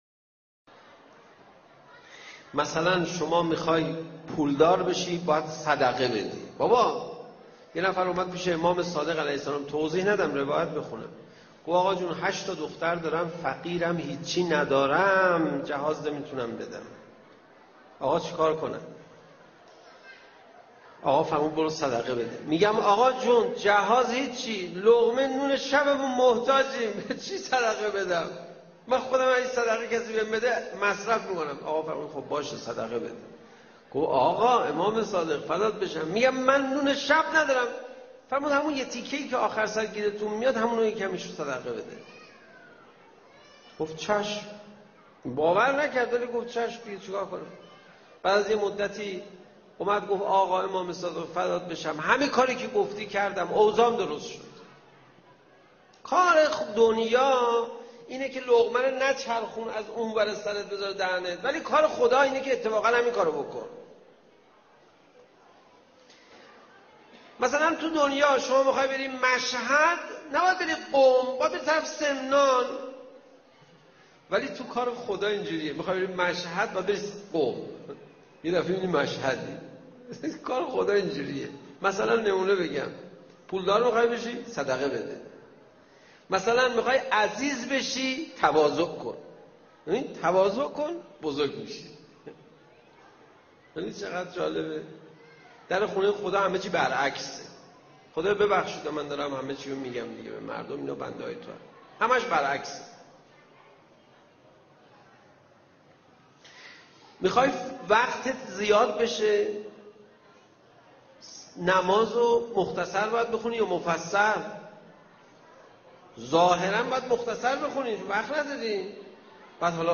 یک راه کار ساده برای پول دار شدن در کلام اهل بیت علیهم السلام از زبان خطیب توانا حاج آقا پناهیان